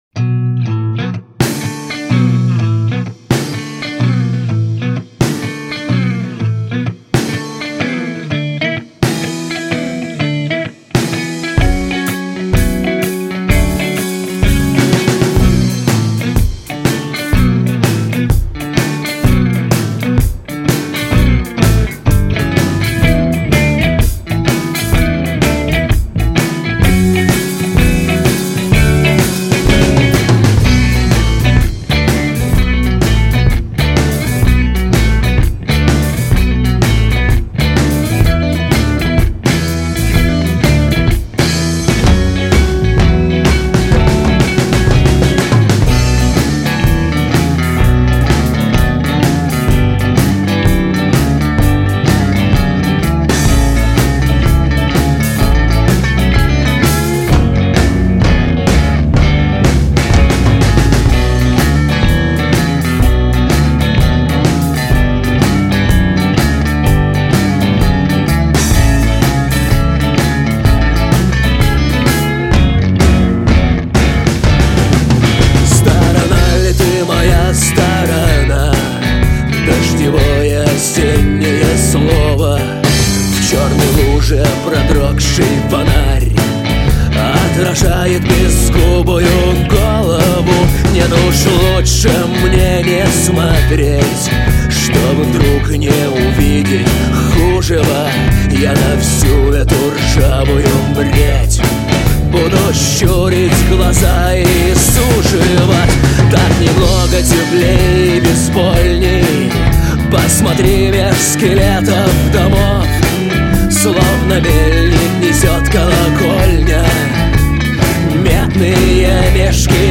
ритм-гитара, акустическая гитара, бас-гитара.
соло-гитара.
ударные.